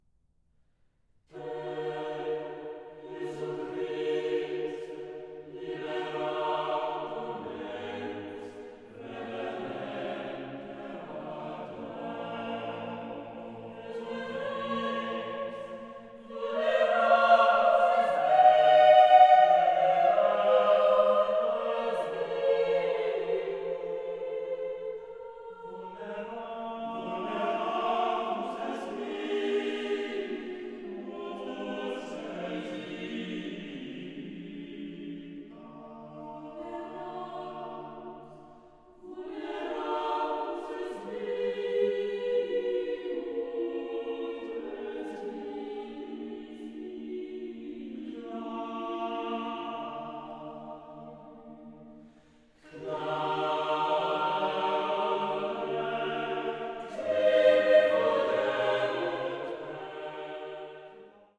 soprano
tenors
organ
sounding revelatory as choral works in sacred guise.